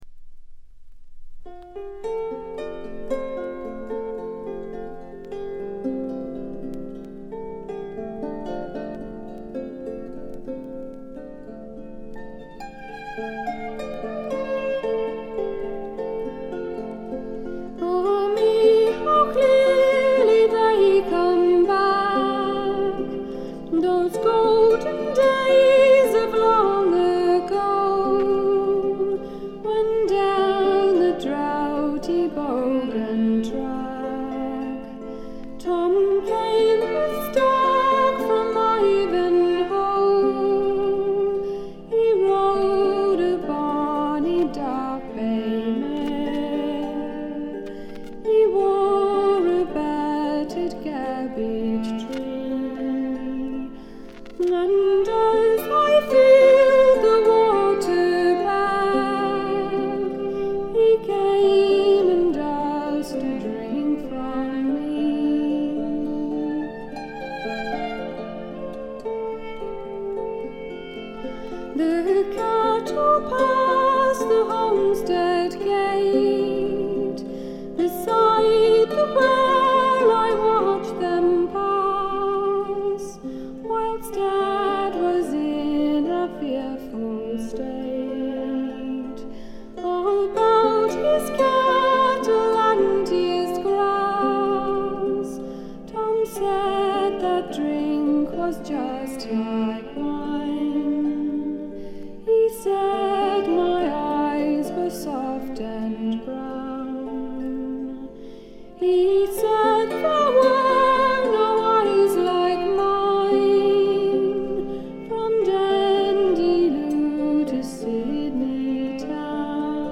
トラディショナル・ソングとトラッド風味の自作曲を、この上なく美しく演奏しています。
試聴曲は現品からの取り込み音源です。
vocal, harp, banjo
viola.